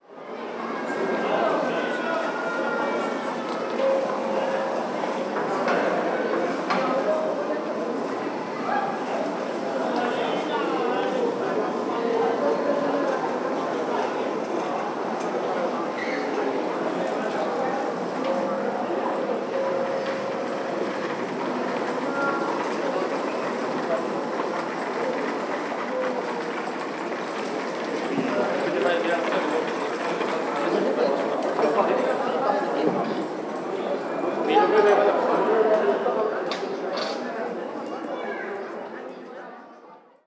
Ambiente de una plaza con gente
plaza voz tránsito terraza ambiente barullo gente murmullo niño ruido sonido
Sonidos: Gente Sonidos: Ciudad Sonidos: Hostelería